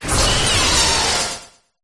duraludon_ambient.ogg